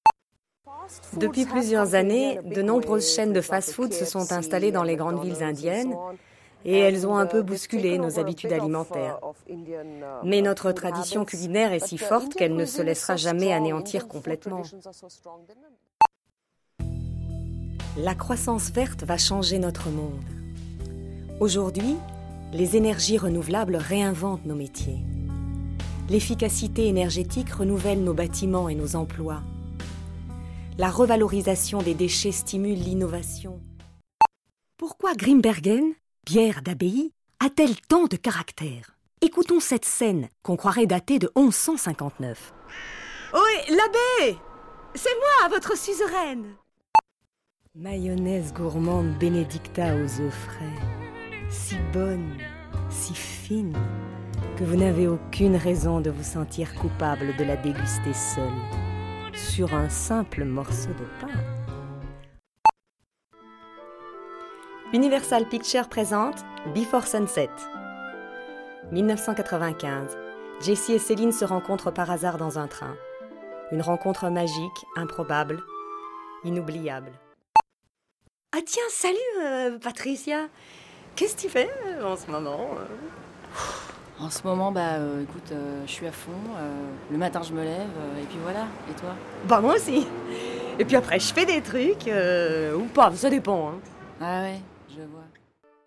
Voix off
- Mezzo-soprano